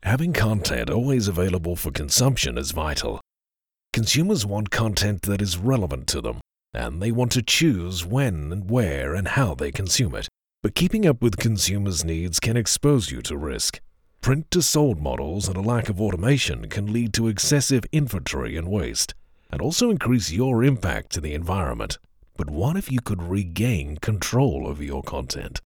Male
Adult (30-50)
Professional,Corporate sound that cutz above the clutter.
Versatile with Presence and resonance.
Mid Atlantic/Neutral Australian
E-Learning
0709Mid_Atlantic.mp3